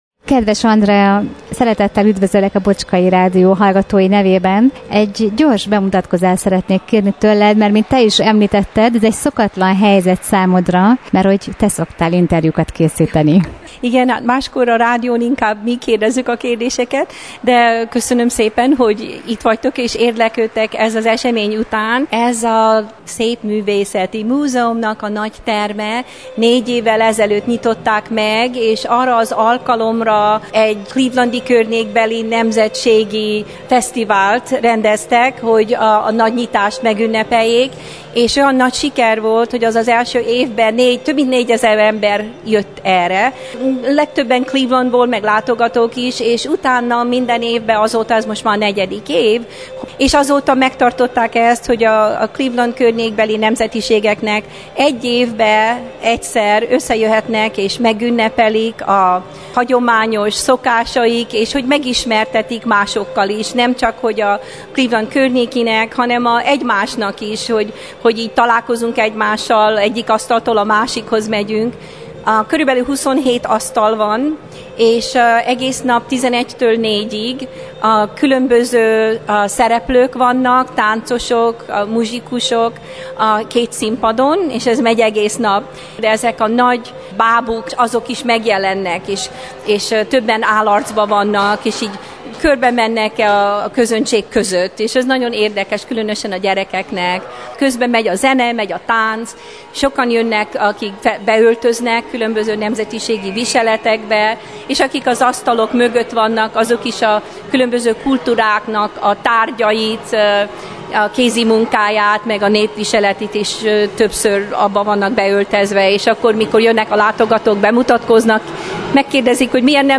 Színes forgatag fogadott a gyönyörű clevelandi Szépművészeti Múzeum épületében, ahol 11órától 16 óráig Cleveland-ban és környékén élő nemzetiségek mutatkoztak be, ismertették meg magukat a látogatókkal.